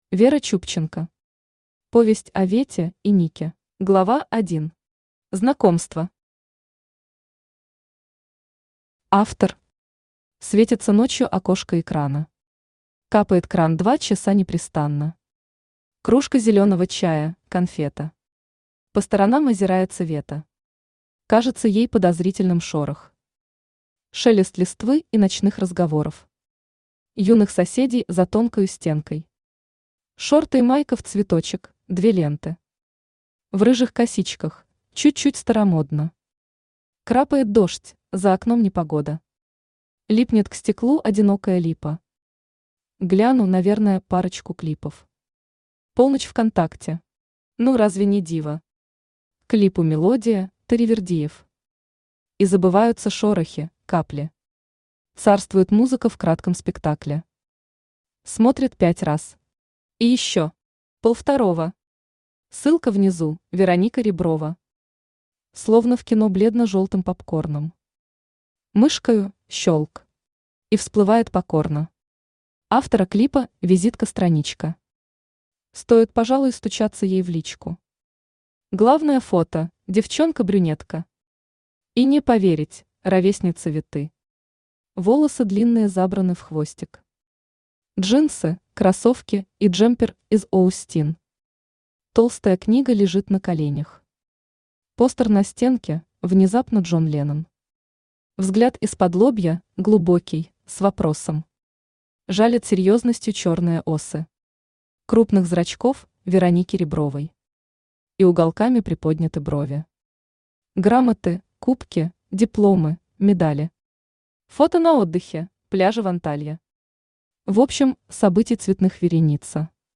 Аудиокнига Повесть о Вете и Нике | Библиотека аудиокниг
Aудиокнига Повесть о Вете и Нике Автор Вера Чубченко Читает аудиокнигу Авточтец ЛитРес.